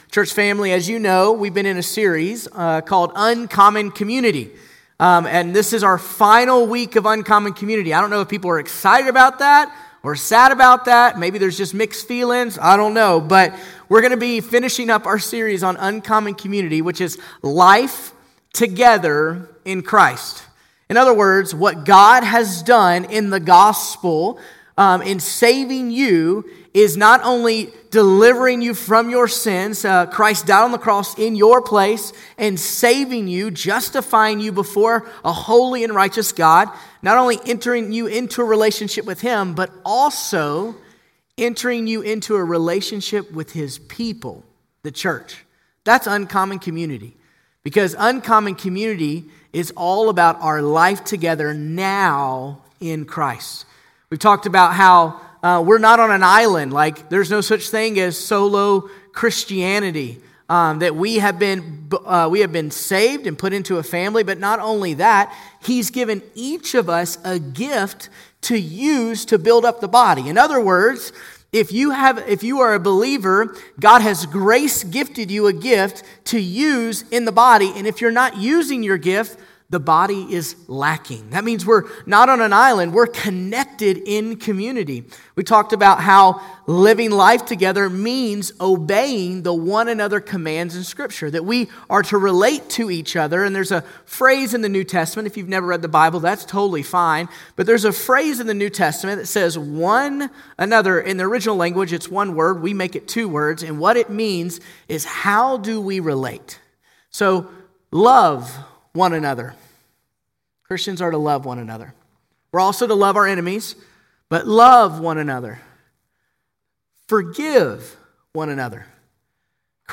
October19sermon.mp3